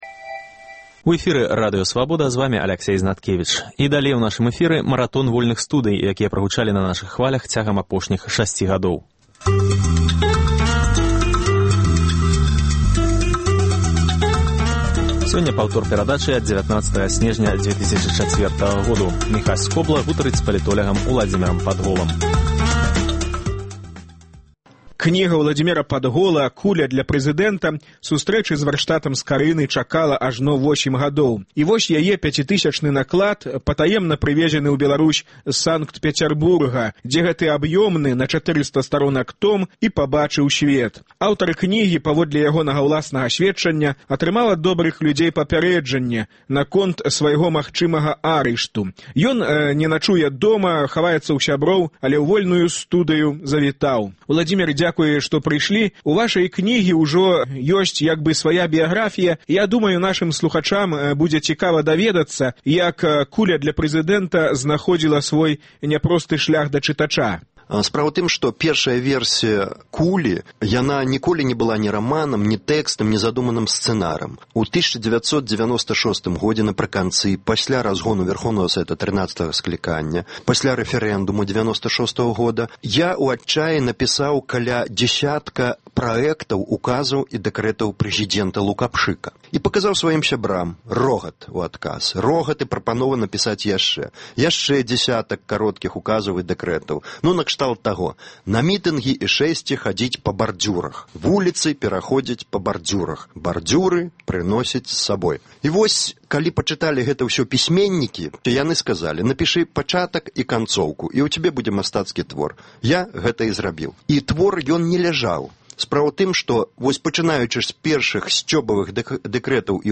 Паўтор перадачы ад 12 сакавіка 2006 году.